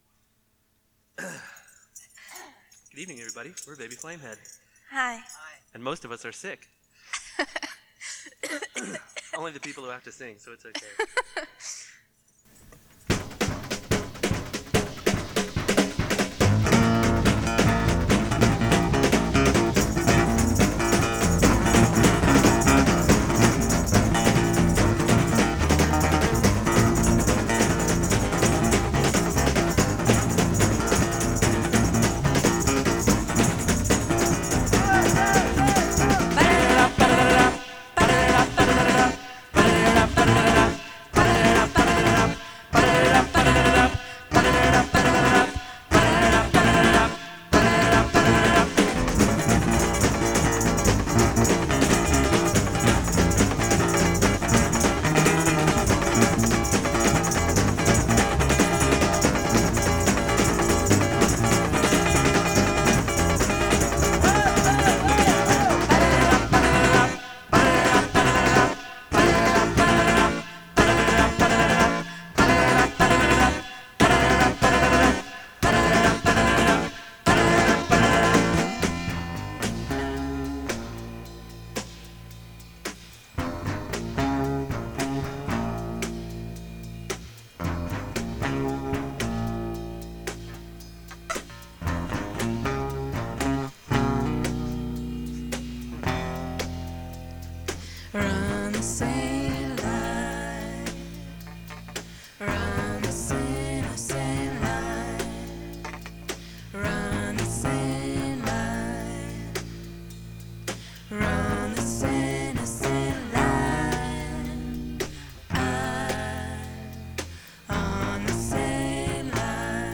acoustic band
on guitar
on drums
vocals